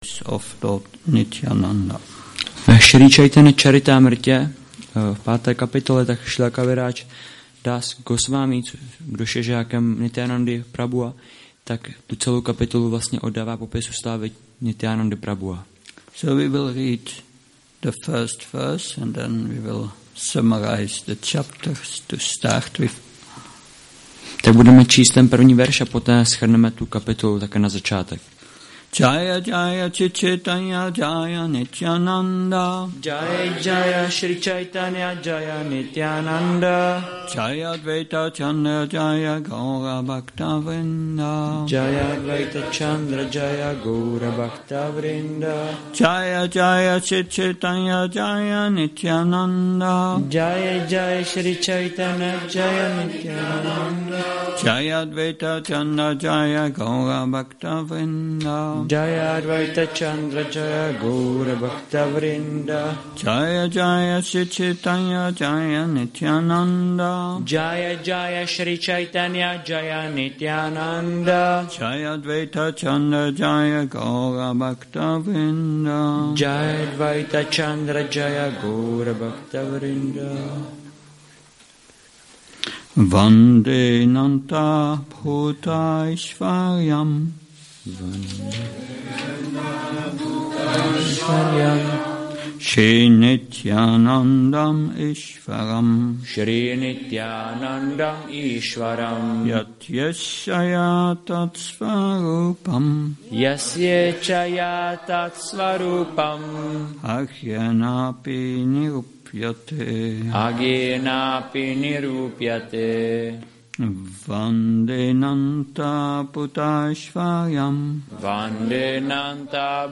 Šrí Šrí Nitái Navadvípačandra mandir
Přednáška CC-ADI-5.1 – Sri Nityananda trayodasi